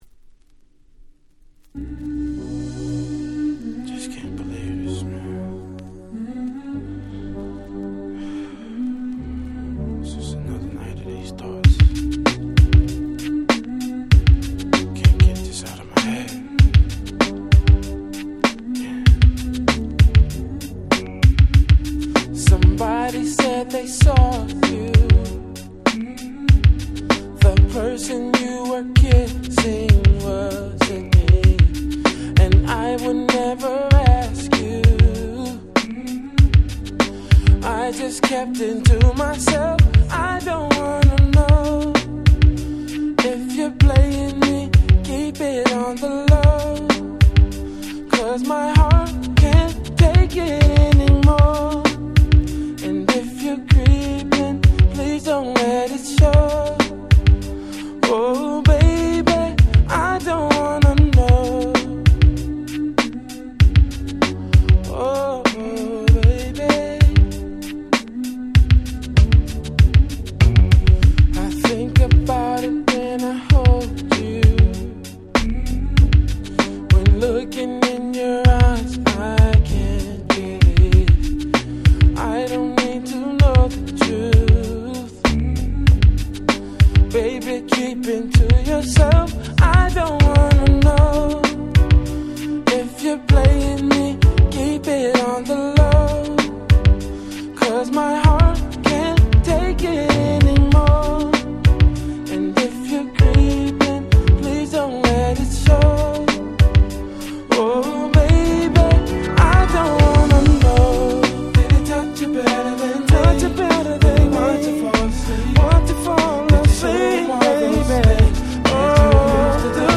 【Media】Vinyl 12'' Single
04' Smash Hit R&B !!
2サビ後からの展開が気持ち良すぎます！！